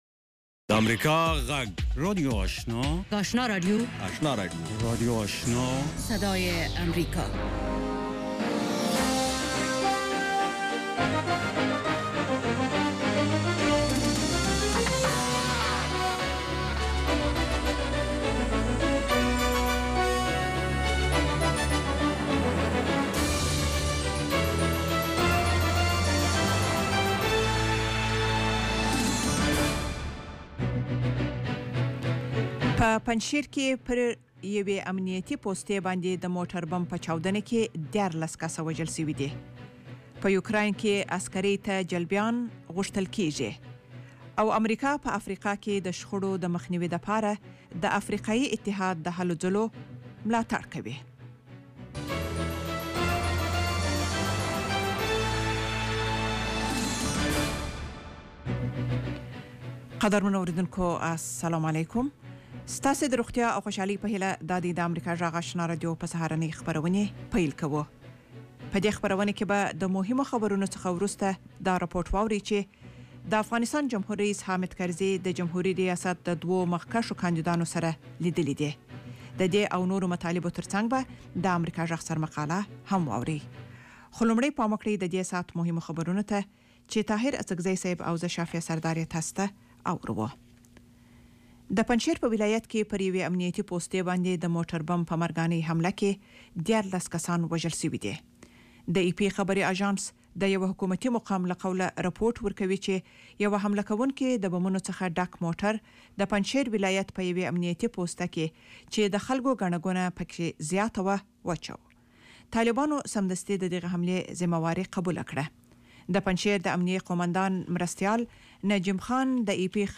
یو ساعته پروگرام: خبرونه، د سیمییزو او نړیوالو مسایلو په اړه رپوټونه، تبصرې، نړیوالو مطبوعاتو ته کتنه، ټولنیز او کلتوري مسایل، اقتصادي او سپورټي خبرونه، تاریخي پیښو ته لنډه کتنه او د ورځې نورې په زړه پورې موضوعگانې او موسیقي وختونه ورځني :د افغانستان په وخت 05:00 په وخت UTC د 0030 :واورئ ایم پي تري | وینډوز میډیا